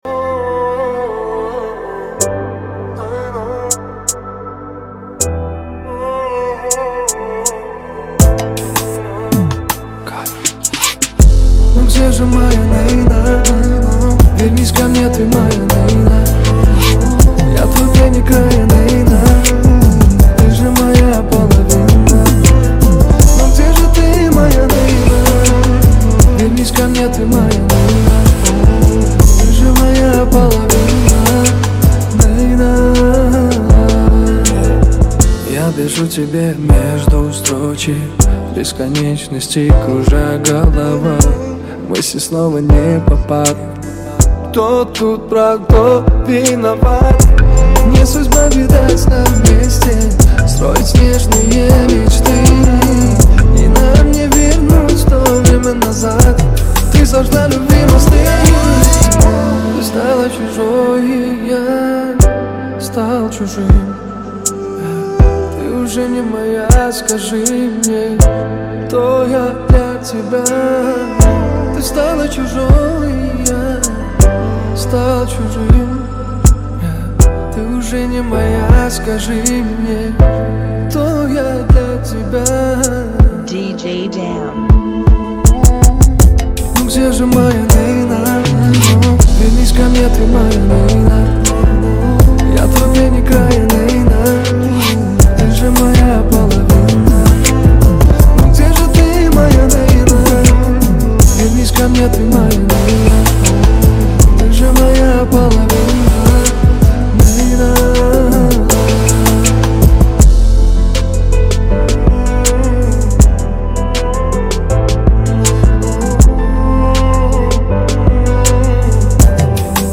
(160 BPM)
Genre: Kizomba Remix